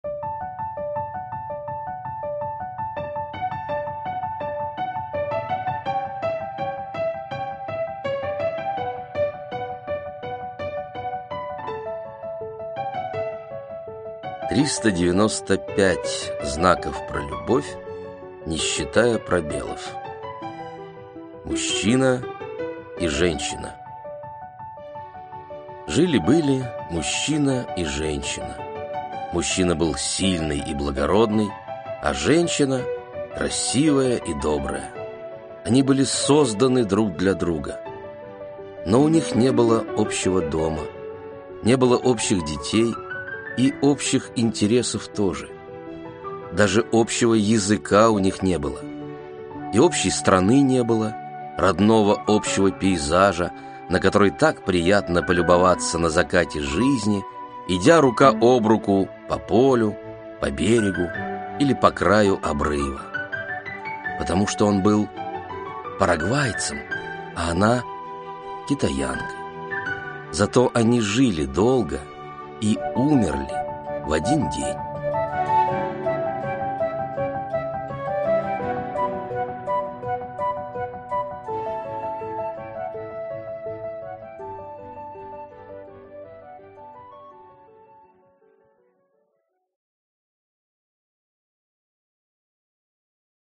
Аудиокнига Интересное кино | Библиотека аудиокниг